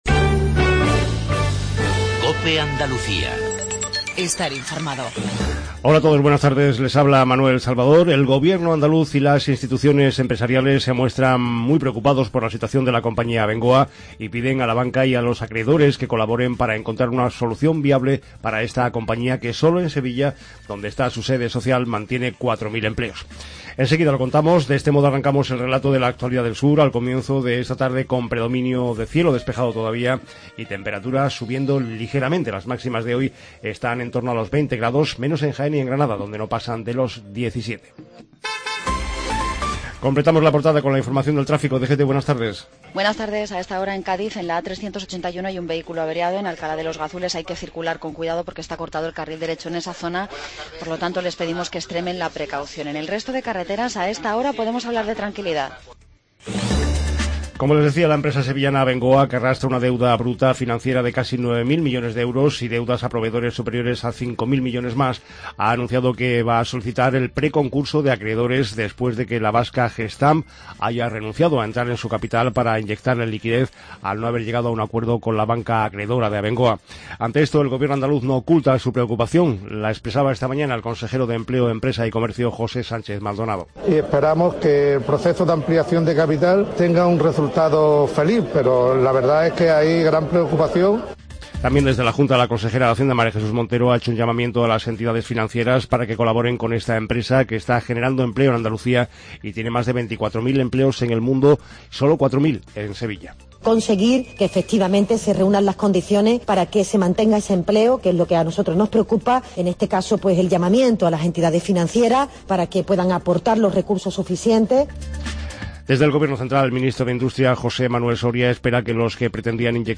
INFORMATIVO REGIONAL/LOCAL MEDIODIA